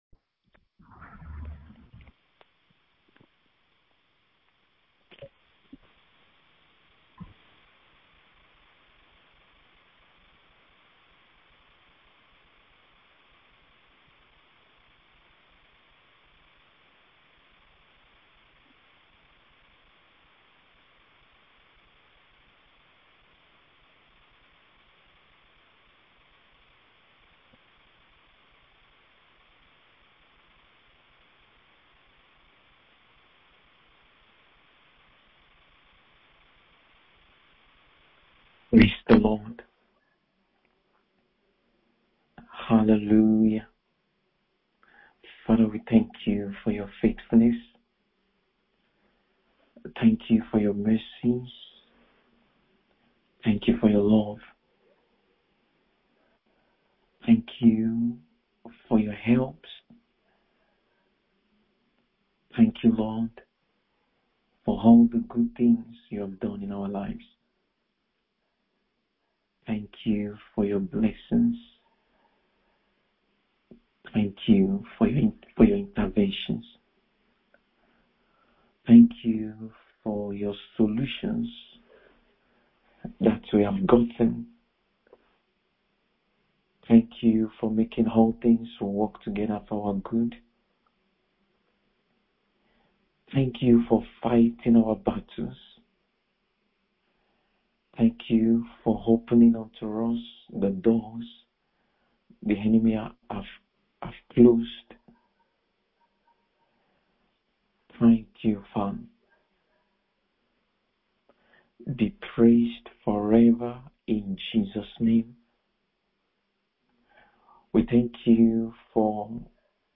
MIDNIGHT PRAYER SESSION : 24 OCTOBER 2024